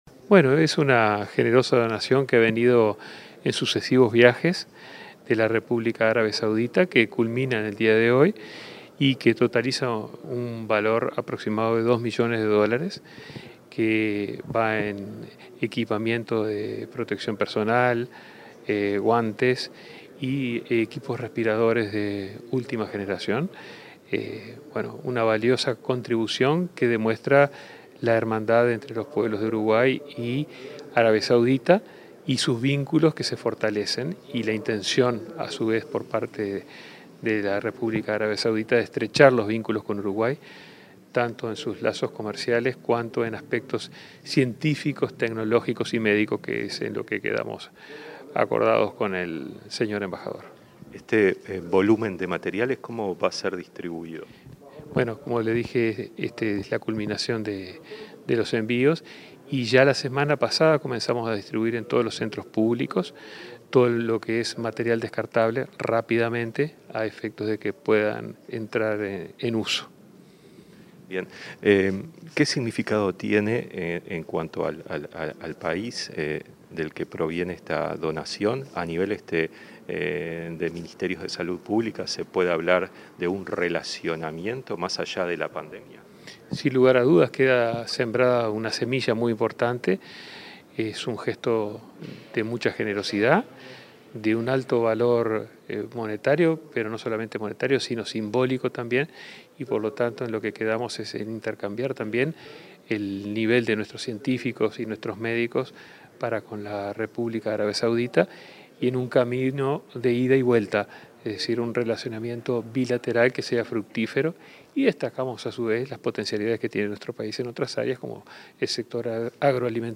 Palabras del ministro de Salud Pública, Daniel Salinas